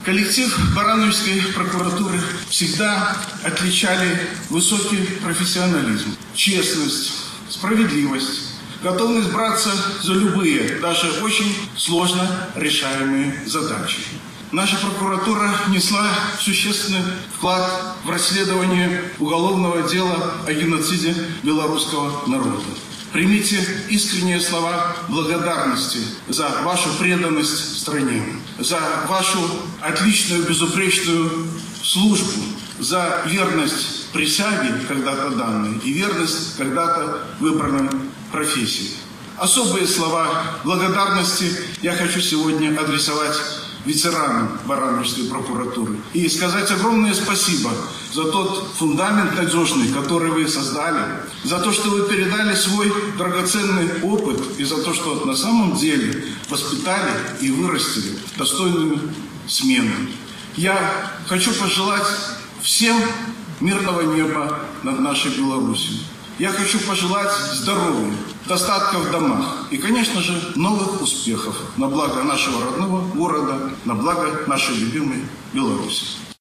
Торжество состоялось в городском Доме культуры.